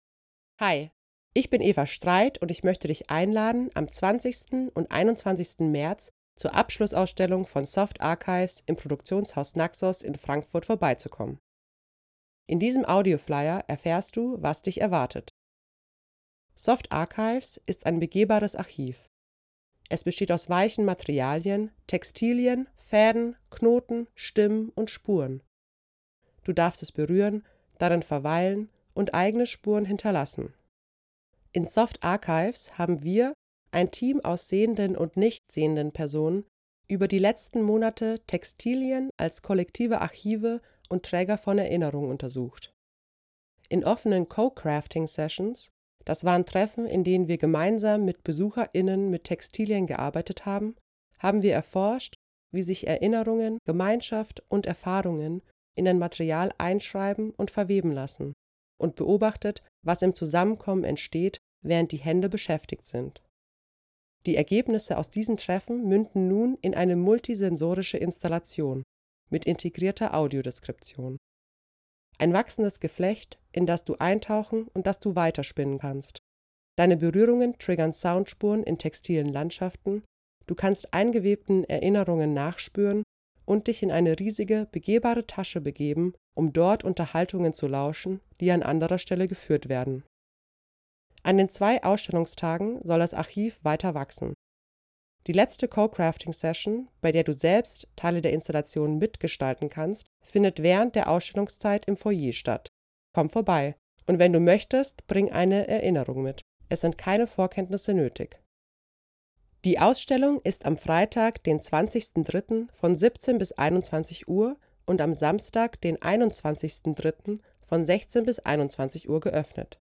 Audiodeskription
SoftArchives_Audio-Flyer.wav